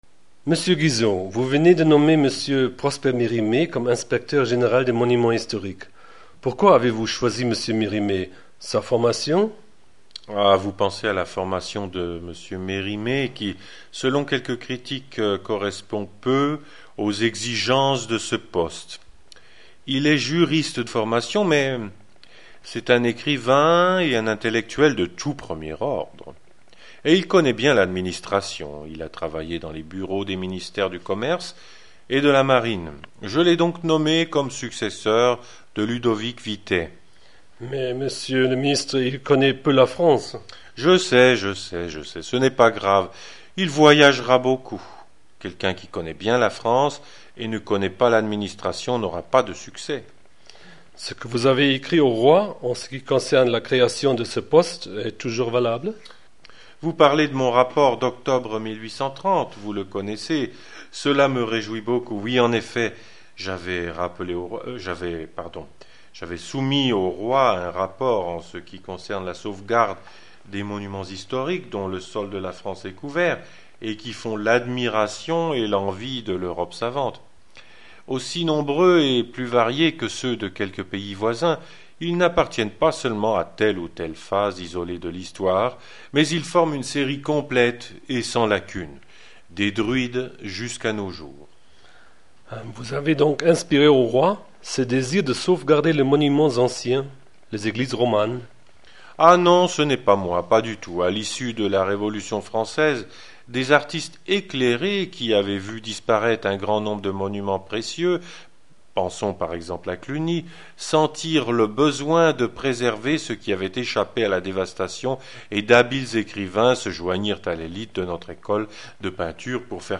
Er hat mich in seinem Büro empfangen. Wir haben über Mérimée gesprochen, er hat mir die Aufgabe erläutert, die er ihm anvertraut hat, und ich konnte den Minister nach einige Schlüsselbegriffen seiner Geschichtsvorlesung fragen: